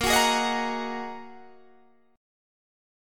Bb7sus4#5 chord